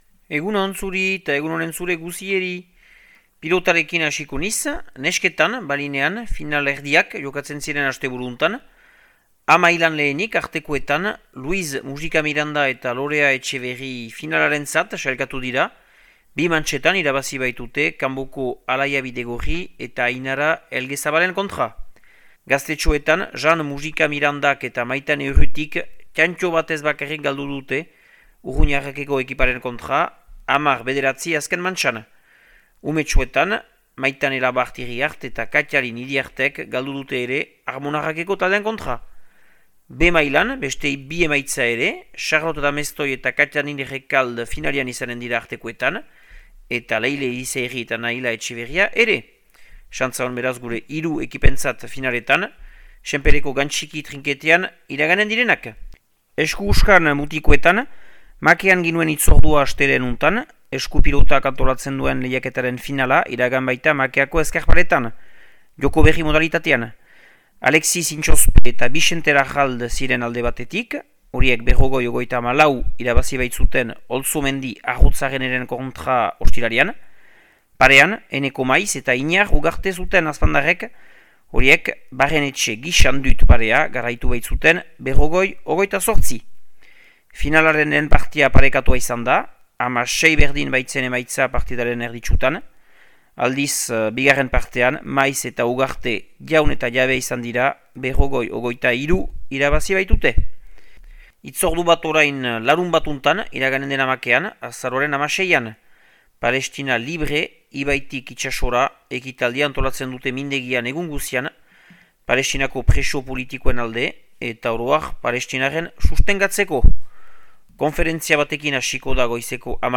Azaroaren 12ko Makea eta Lekorneko berriak